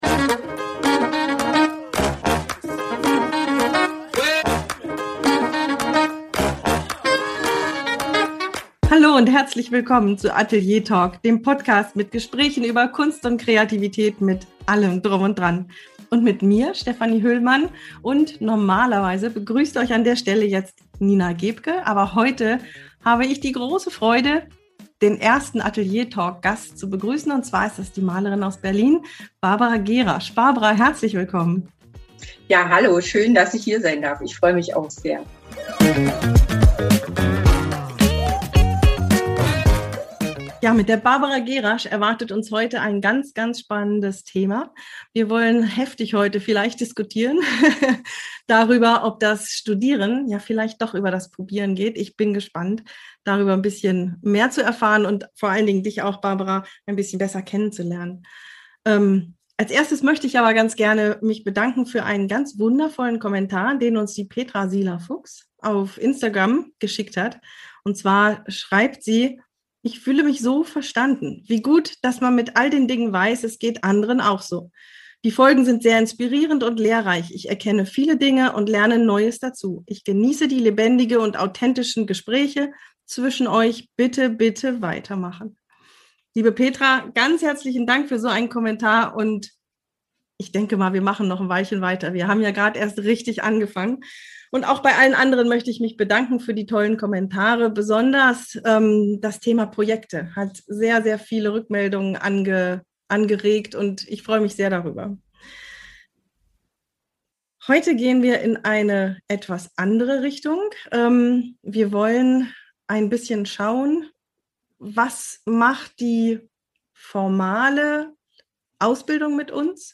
Im Gespräch mit einer bildenden Künstlerin auf den zweiten Anlauf